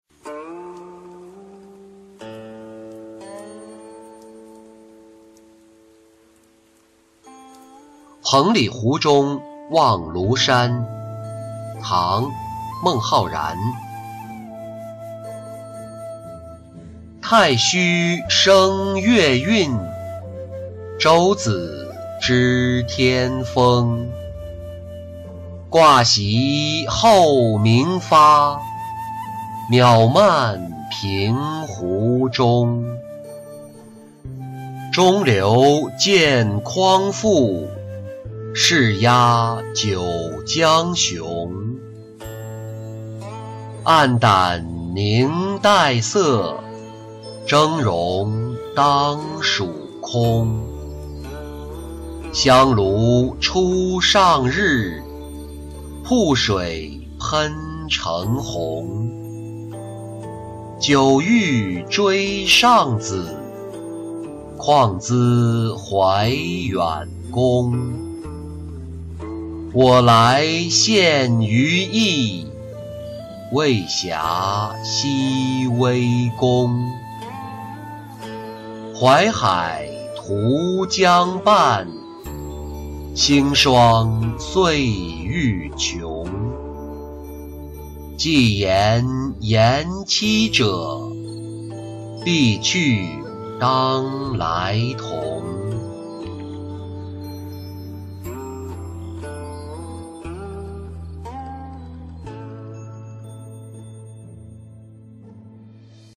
彭蠡湖中望庐山-音频朗读